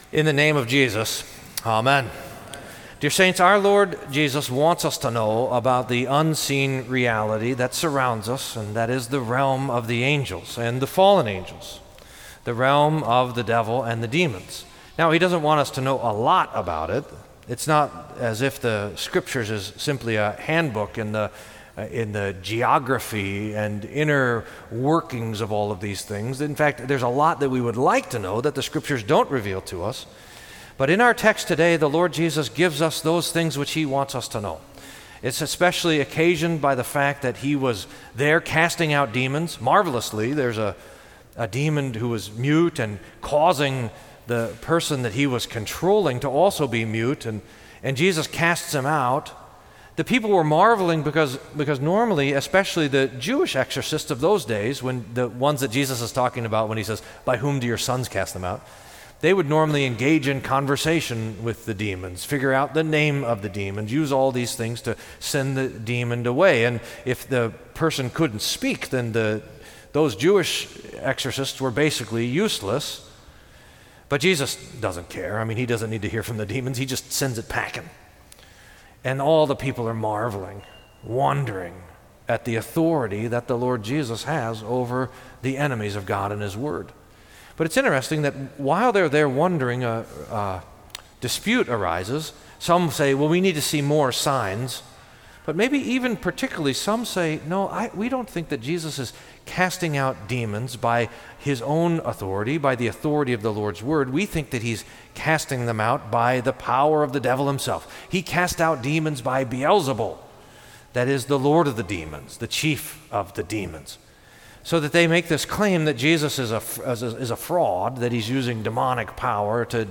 Sermon for Third Sunday in Lent